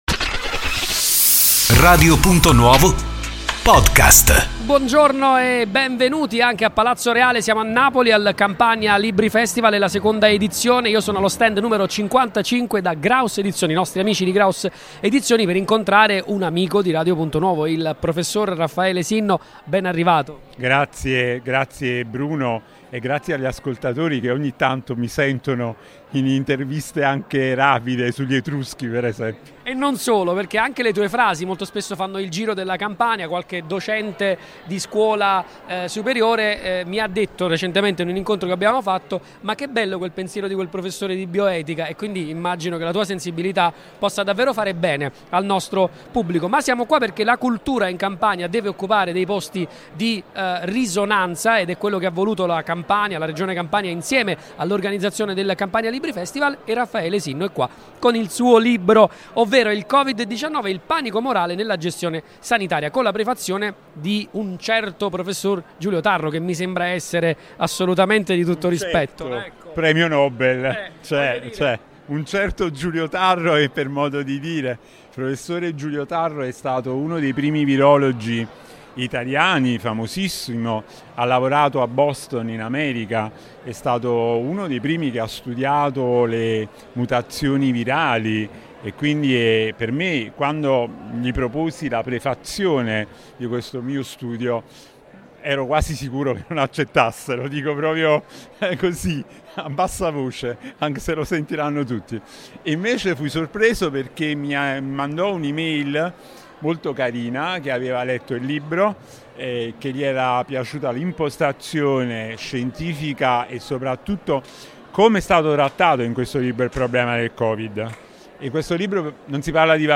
Un vero e proprio bagno di folla a Palazzo Reale , a Napoli, per la Seconda Edizione del Campania Libri Festival .